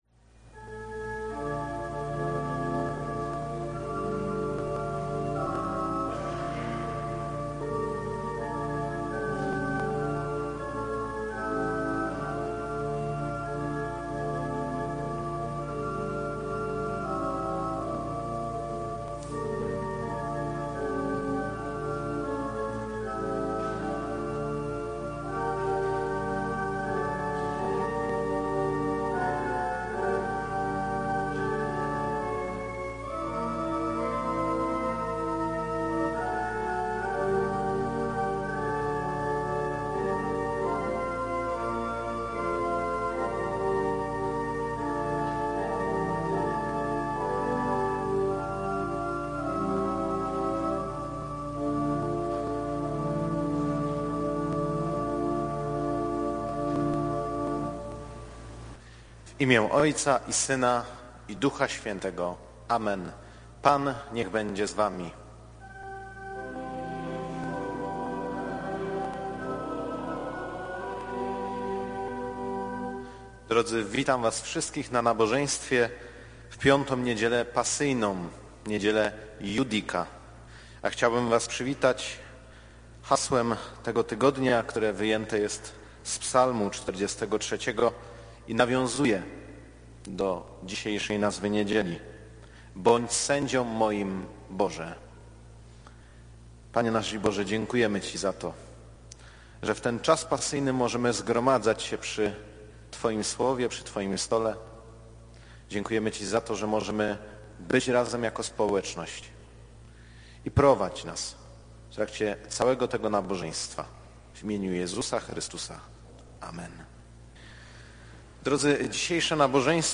W piątkowy wieczór (12 lutego) odbyło się w Parafii spotkanie dotyczące sytuacji ewangelików żyjących w Wschodniej Ukrainie.
Spotkanie zostało nagrane, więc można je jeszcze ODSŁUCHAĆ, do czego serdecznie zachęcamy.